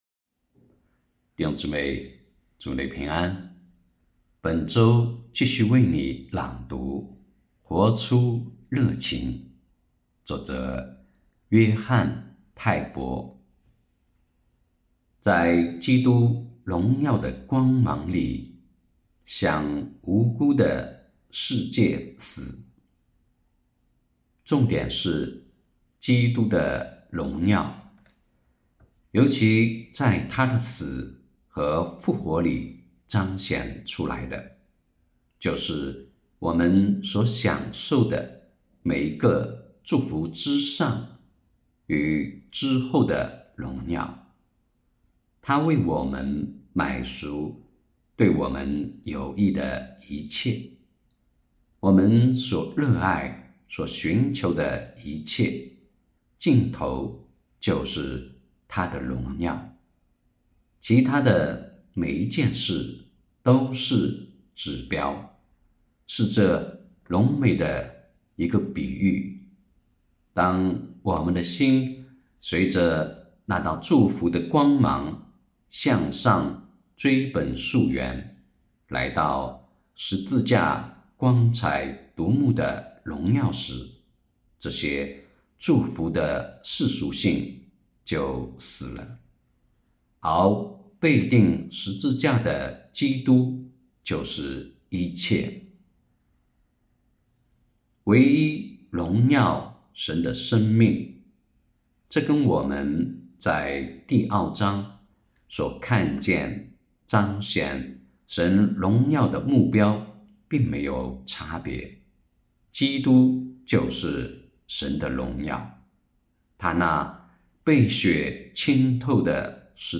2023年10月26日 “伴你读书”，正在为您朗读：《活出热情》 音频 https